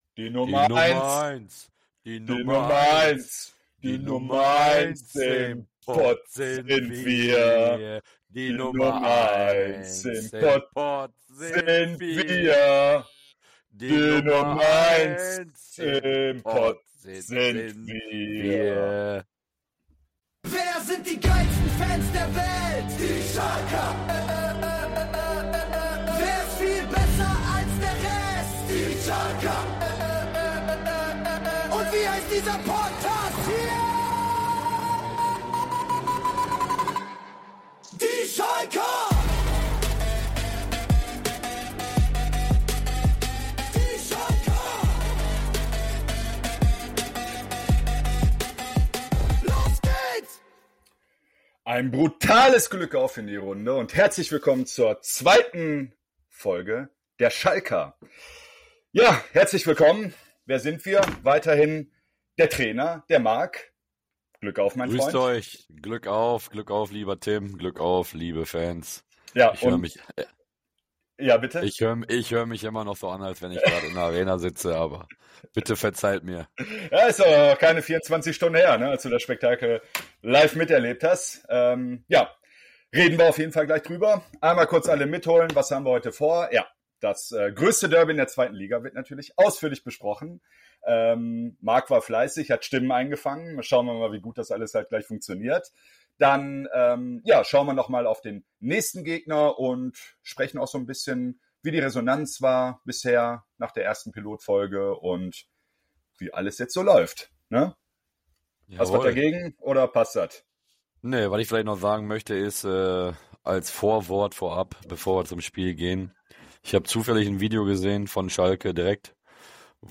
In dieser Folge hört ihr nicht nur unsere Meinung zum Spiel, sondern auch Stimmen direkt von der Tribüne.